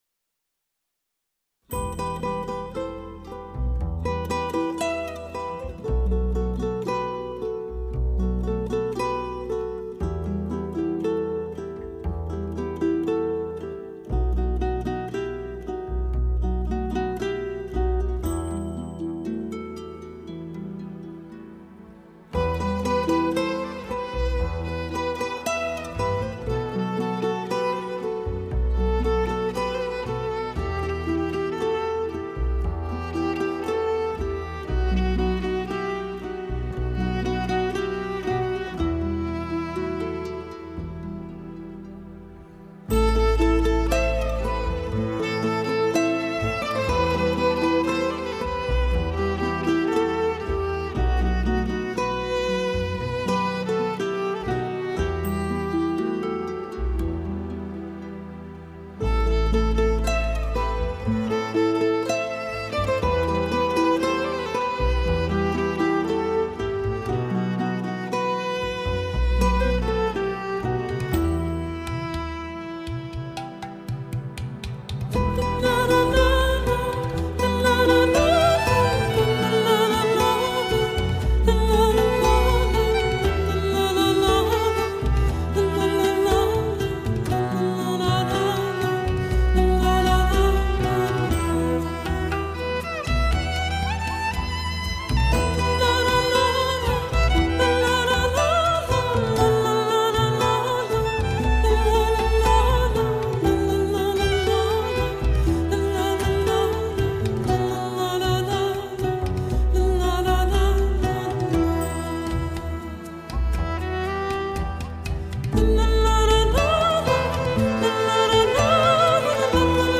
بومی و محلی